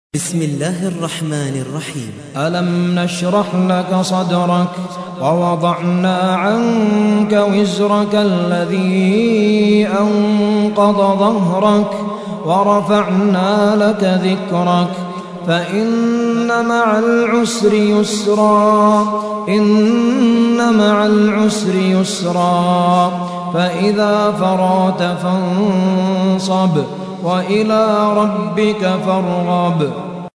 94. سورة الشرح / القارئ